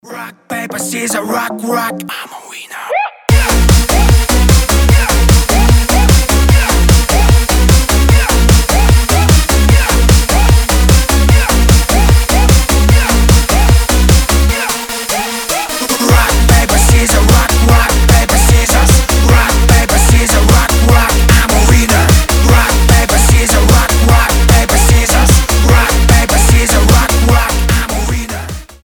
веселые
энергичные
быстрые
Rave